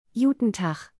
(Please note: the audio pronunciations in this post are in a “Standard German” accent, so they don’t sound exactly as they would coming from a real Berliner.)